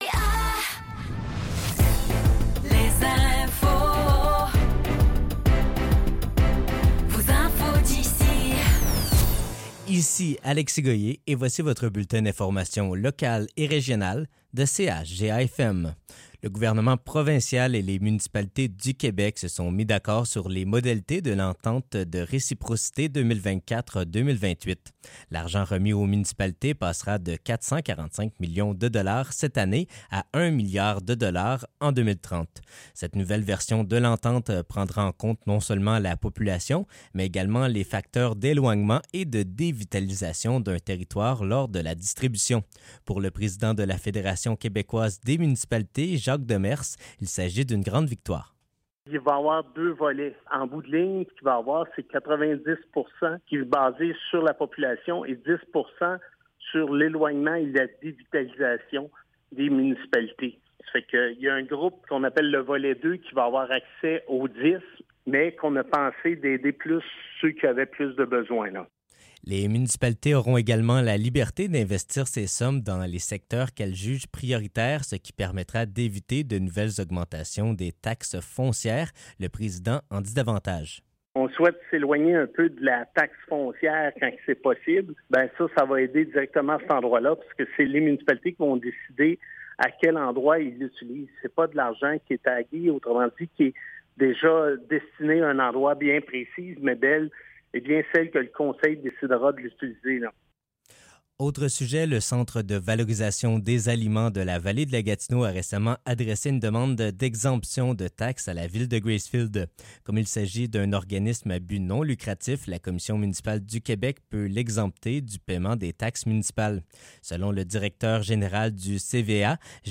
Nouvelles locales - 10 octobre 2024 - 15 h